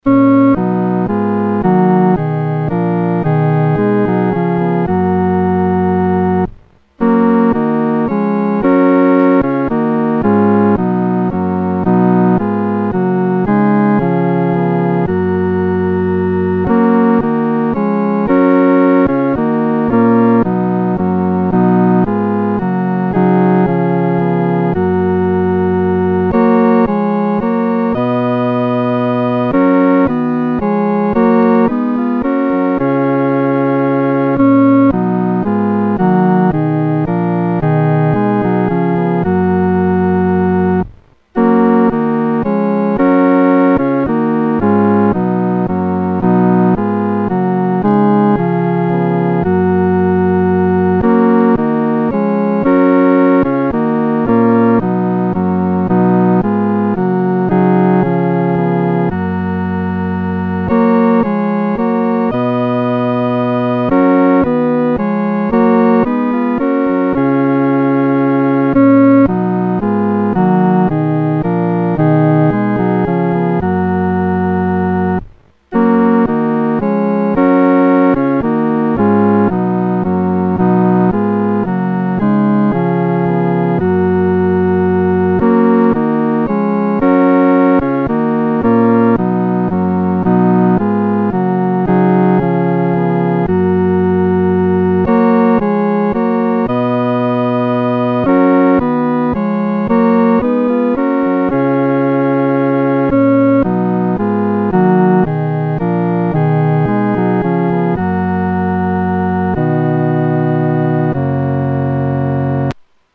独奏（第三声）
赞美全能神-独奏（第三声）.mp3